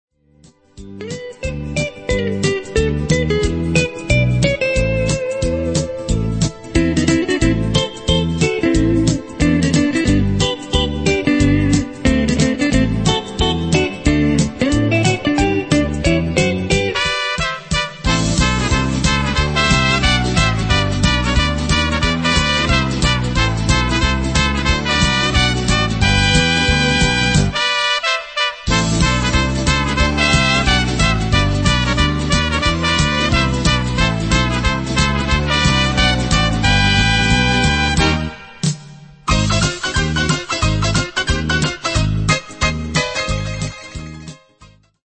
fox